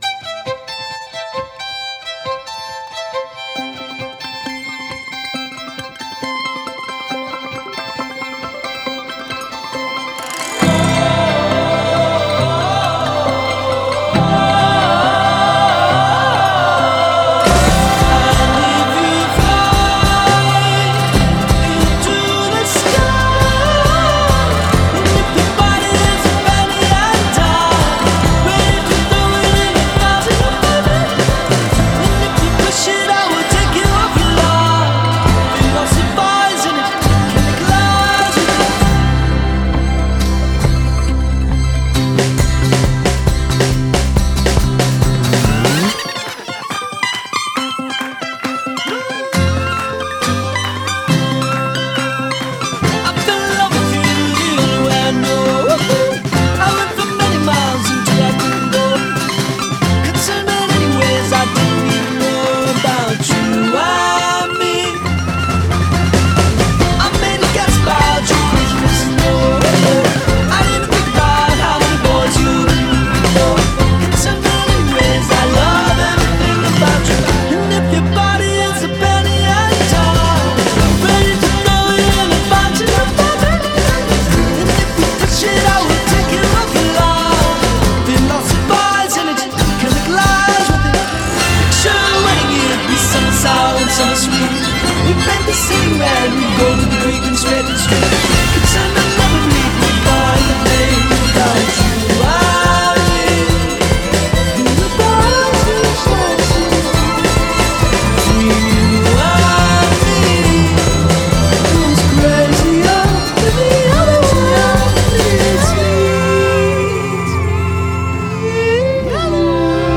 Genre: Indie Pop, Orchestral Pop